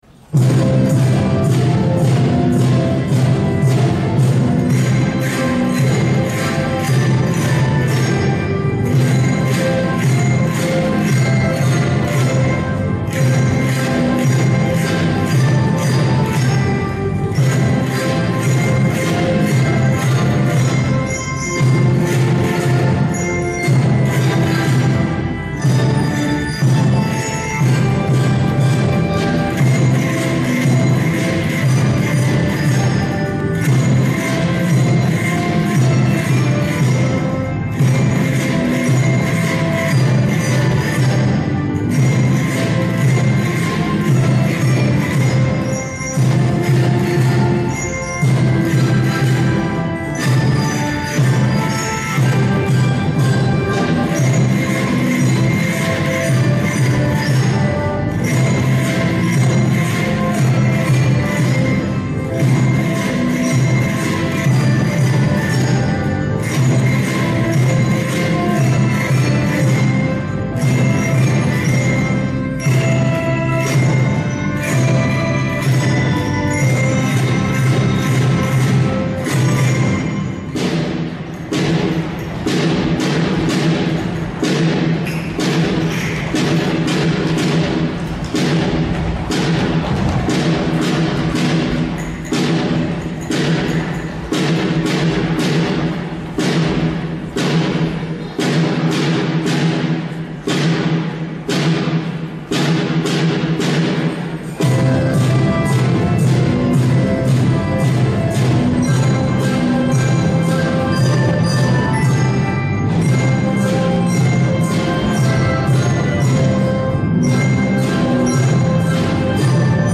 （合奏・リズム表現）
１年生は、前半はカスタネット。軽快にリズムを刻みました。